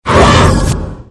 monster3h.mp3